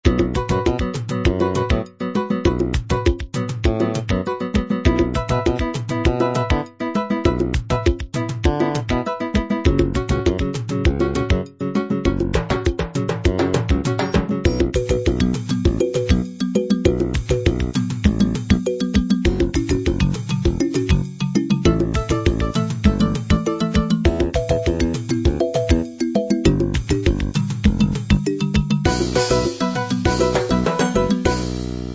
Another 16 beat request.
Fingered E Bass, Kalimba, Nylon Guitar and Slap Bass patch